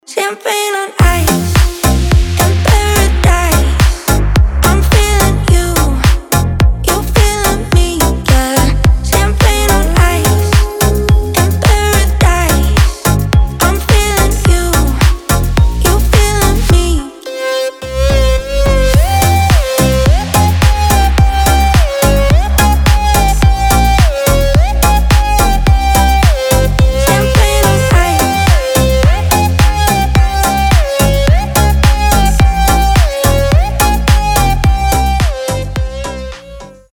• Качество: 320, Stereo
громкие
house